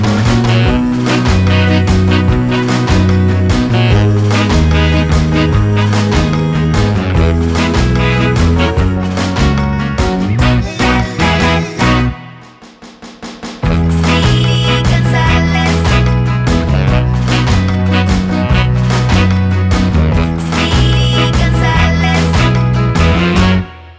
Vocal Only Pop (1960s)